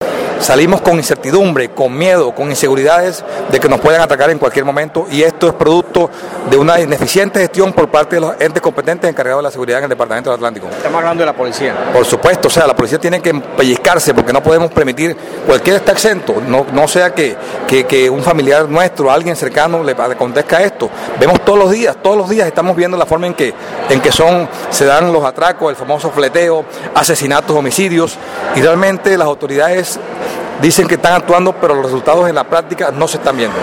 Las declaraciones del diputado Rosales Stell se dieron durante la sesión del día anterior de la Asamblea, en donde se aprobó en segundo debate el Plan de Desarrollo del gobernador, Eduardo Verano.